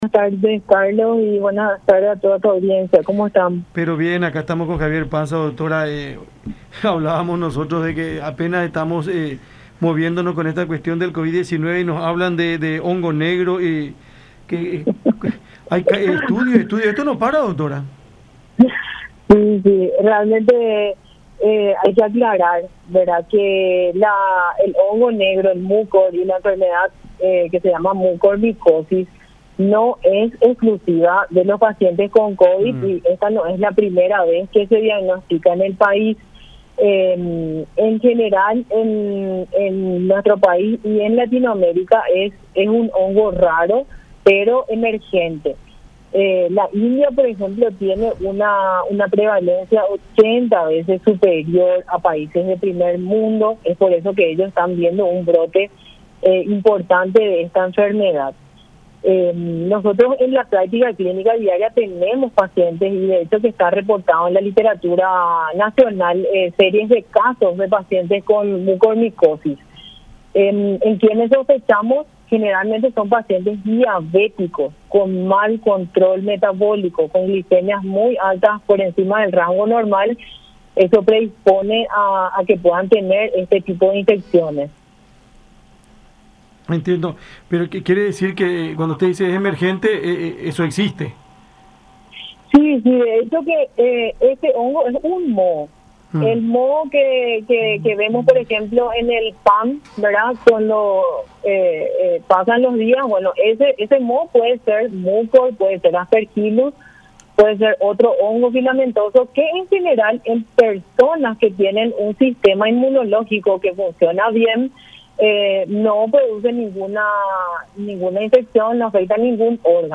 en conversación con Cada Siesta por La Unión.